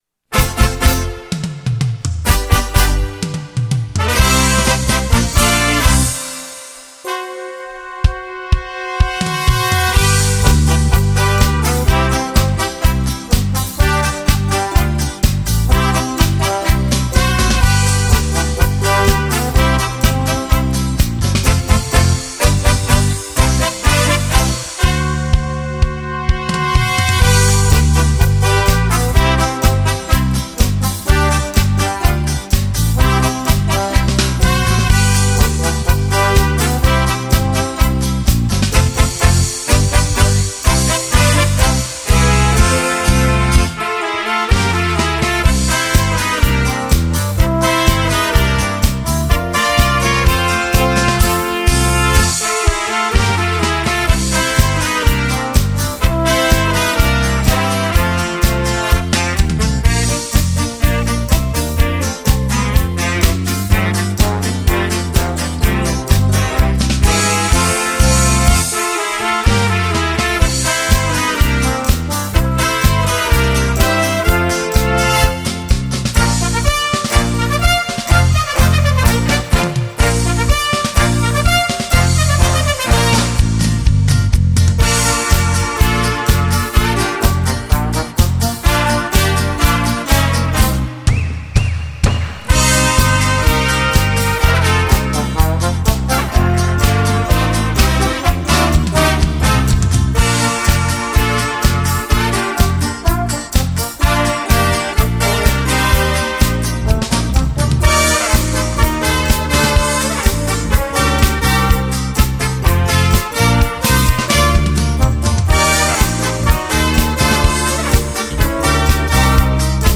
POUT-POURRI INSTRUMENTAL.